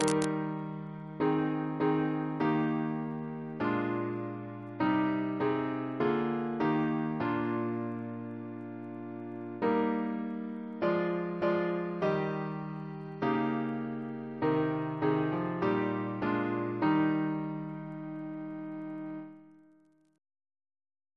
Double chant in E♭ Composer: Sir John Goss (1800-1880), Composer to the Chapel Royal, Organist of St. Paul's Cathedral Reference psalters: ACB: 39; PP/SNCB: 216